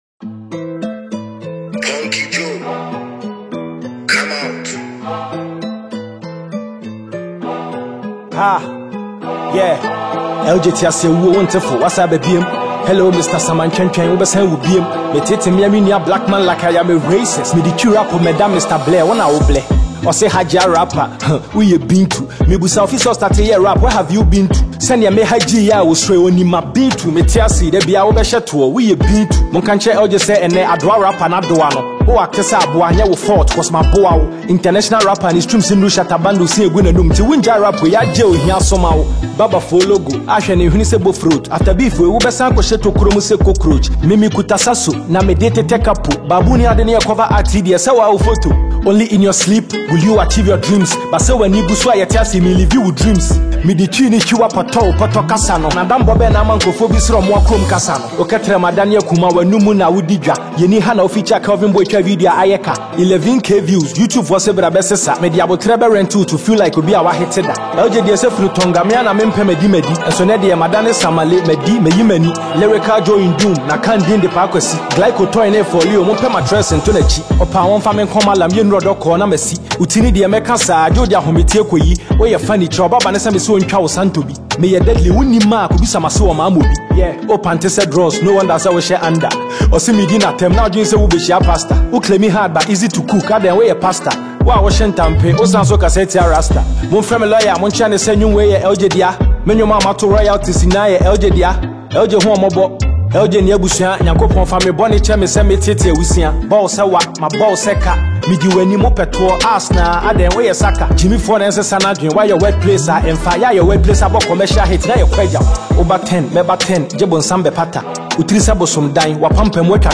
Ghana Music Music
diss song